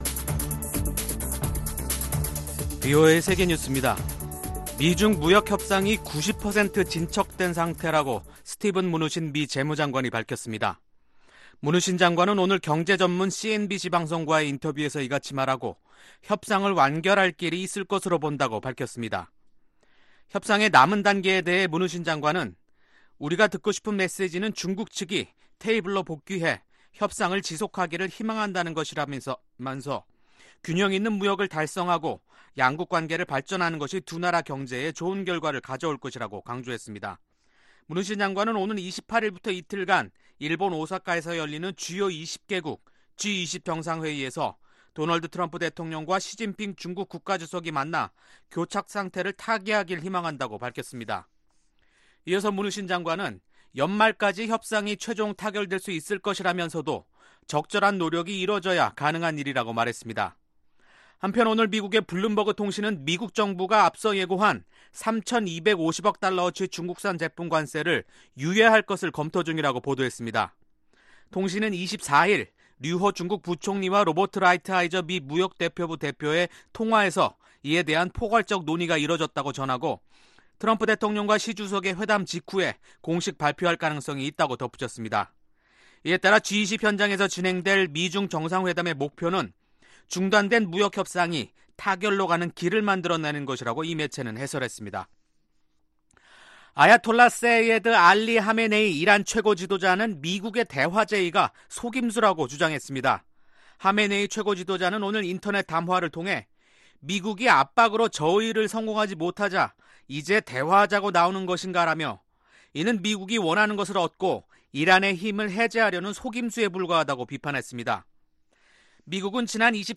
VOA 한국어 간판 뉴스 프로그램 '뉴스 투데이', 2019년 6월 26일 3부 방송입니다. 미국과 북한이 3차 정상회담을 위한 대화를 진행 중이라고 문재인 한국 대통령이 밝혔습니다. 트럼프 미국 대통령의 한국 방문을 앞두고 미 국무부는 전제 조건 없이 북한과 협상할 준비가 돼 있다며 대화 의지를 거듭 강조하고 있습니다.